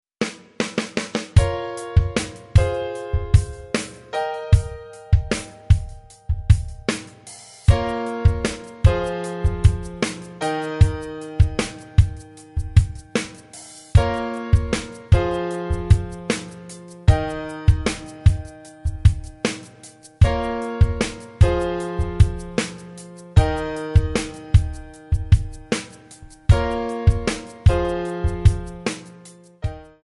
Backing track Karaoke
Pop, Rock, 1990s